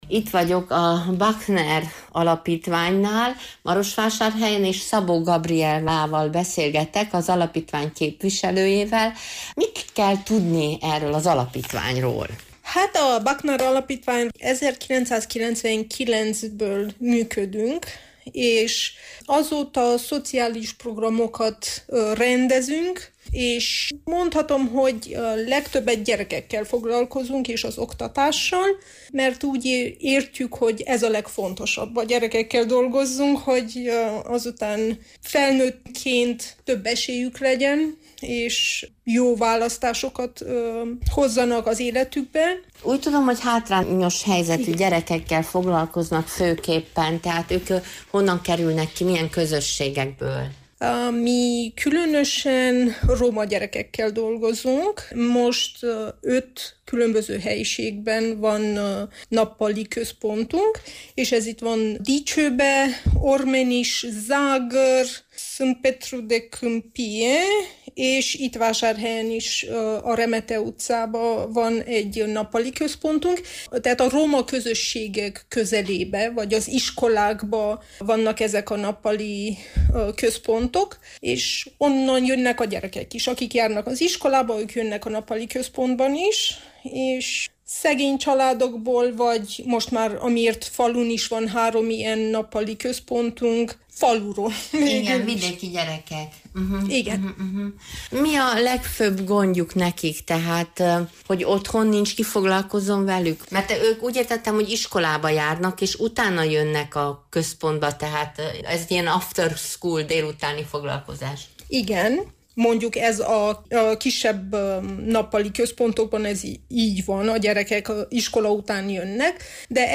Múlt héten a Buckner Alapítvány marosvásárhelyi székhelyén jártunk